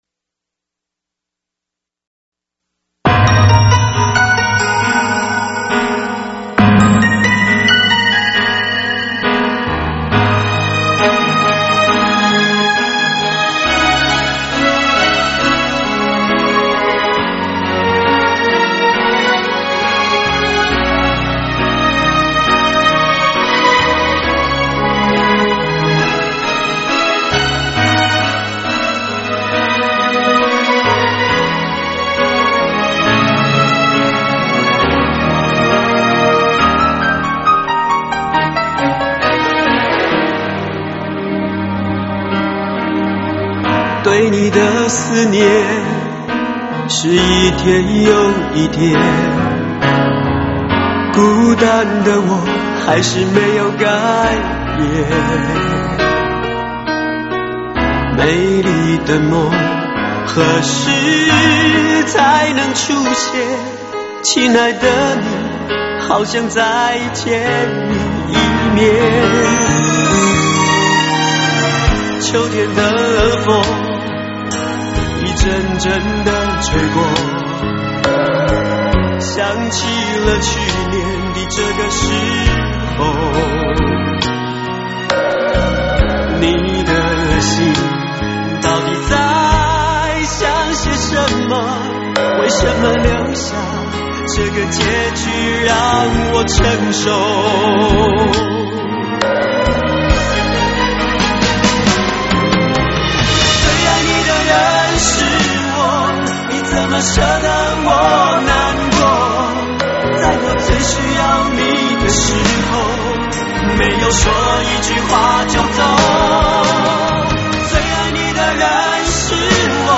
5.1环绕声特赏版